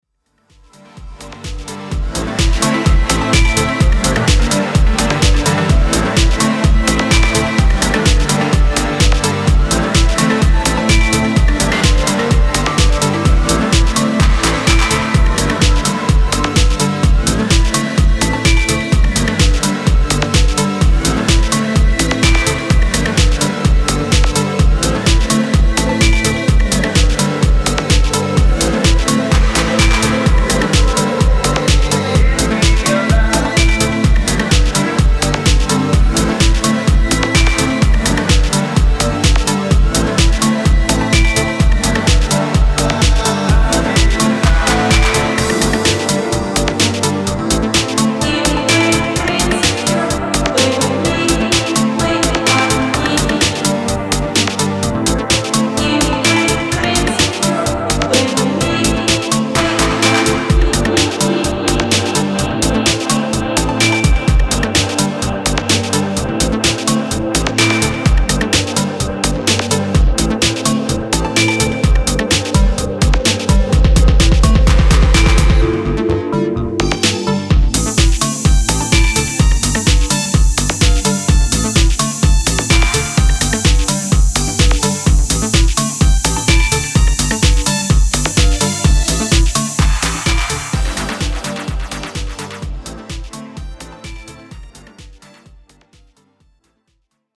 talo-disco inspired house music
New York/Moscow duo
A warm, galloping... more...